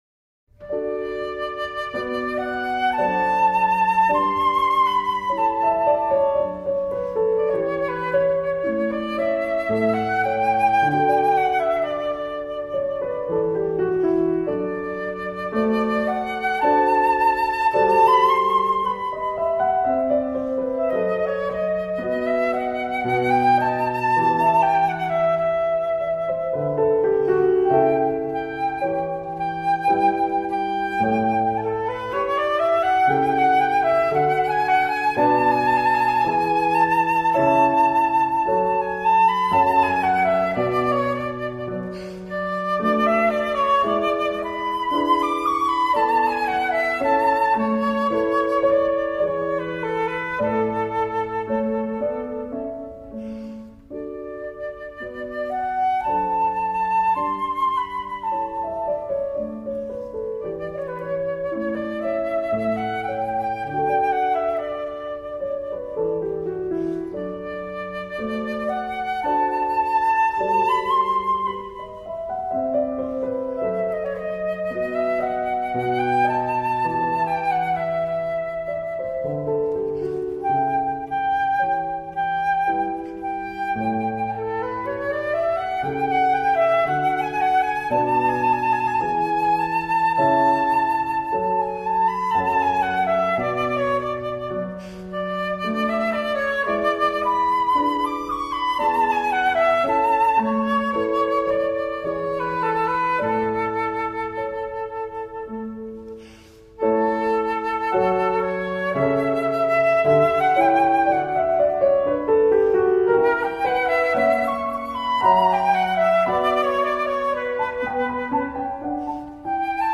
Recurso que contiene un audio de 3 minutos de duración con música instrumental de relajación.